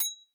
sqeeeek_bell_ting2
bell ding ping short ting sound effect free sound royalty free Sound Effects